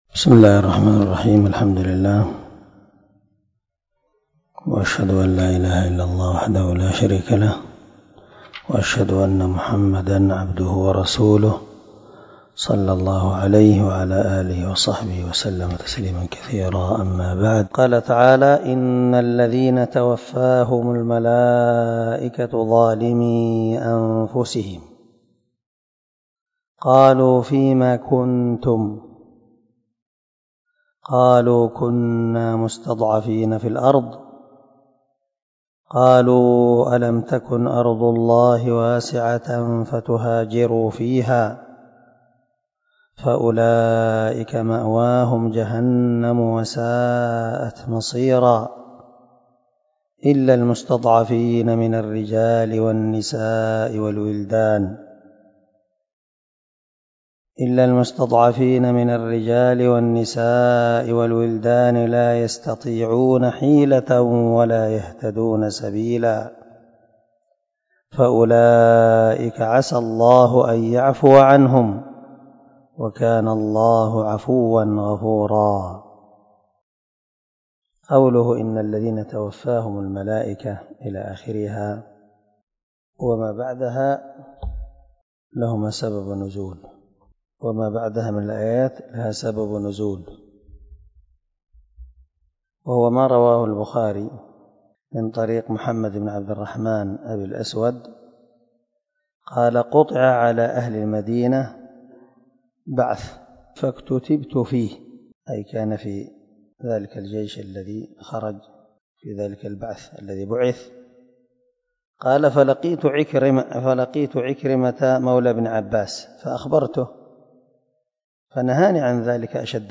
297الدرس 65 تفسير آية ( 97 – 99 ) من سورة النساء من تفسير القران الكريم مع قراءة لتفسير السعدي